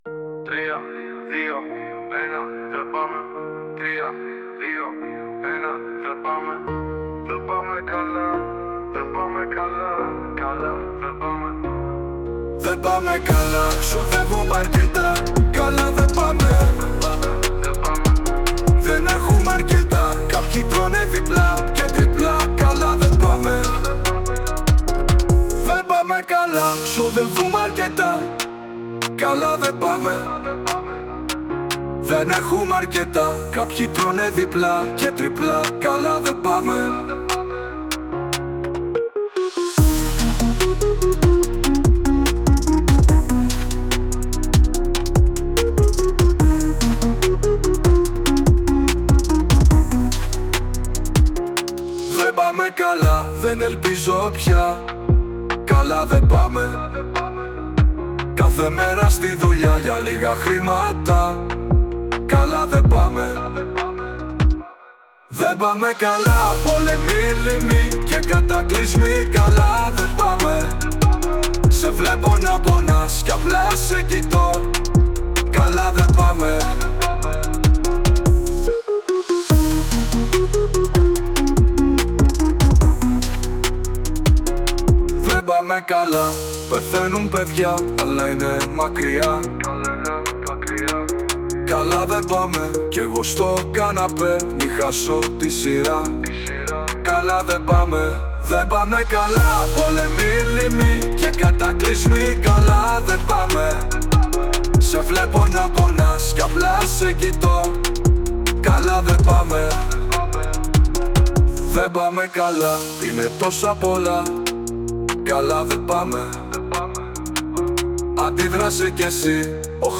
Υπό τους ήχους της… trap μουσικής, οι μαθητές απευθύνουν κάλεσμα για ειρήνη και… δράση!
Με τη συμβολή της τεχνητής νοημοσύνης, οι στίχοι των μαθητών ντύθηκαν με μουσική και φωνή.